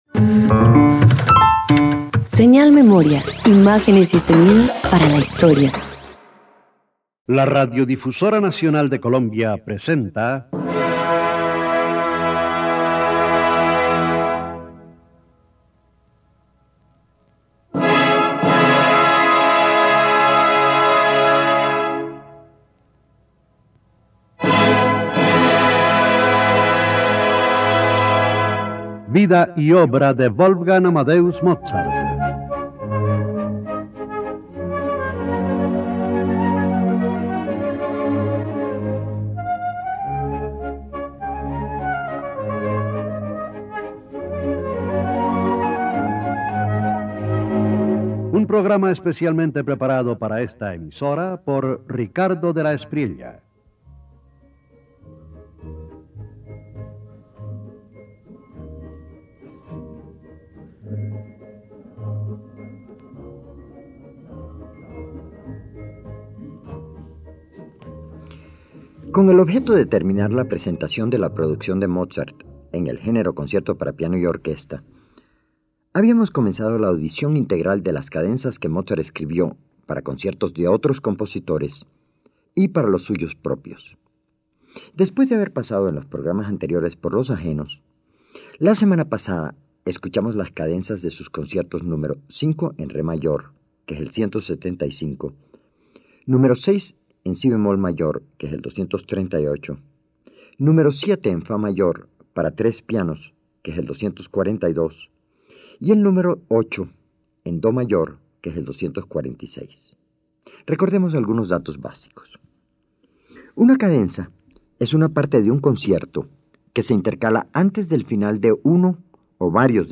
321 Cadenzas para piano y orquesta  Parte II_1.mp3